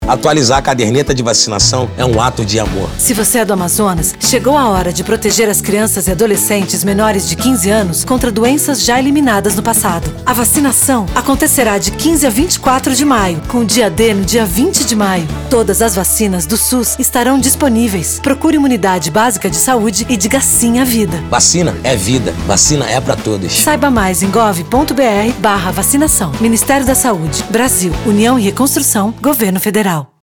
Spot - Multivacinação Amazonas - 30seg .mp3